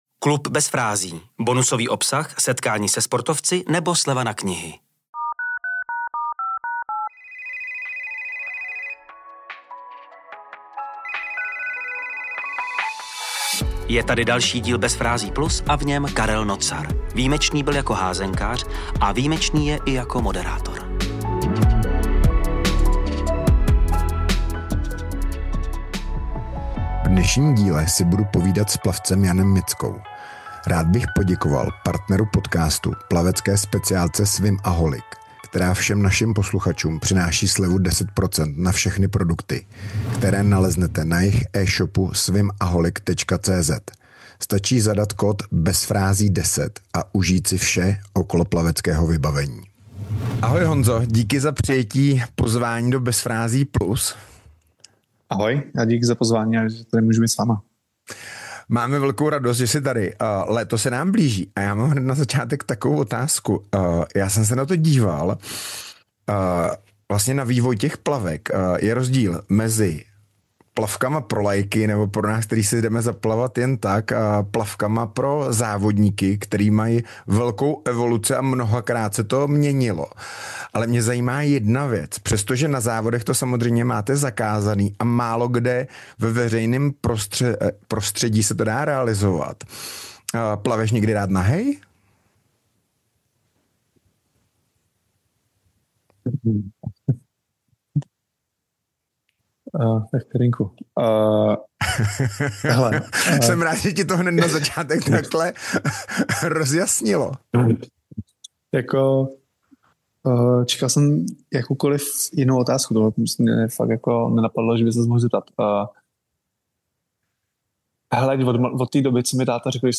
🎧 CELÝ ROZHOVOR pouze pro členy KLUBU BEZ FRÁZÍ.